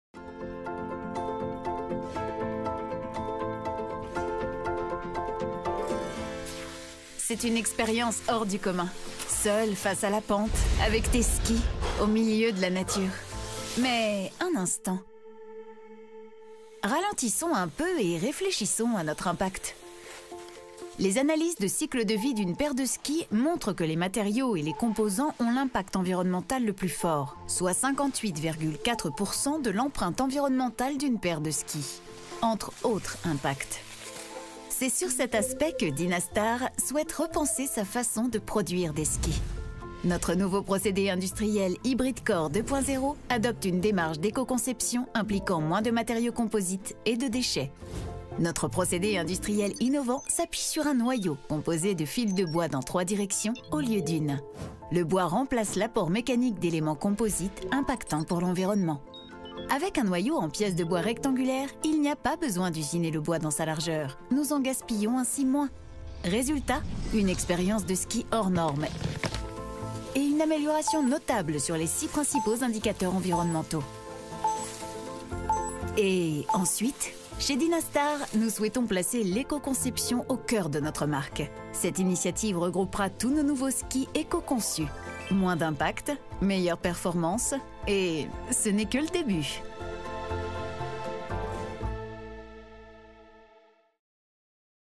Junge, Verspielt, Kommerziell, Natürlich, Vielseitig
Unternehmensvideo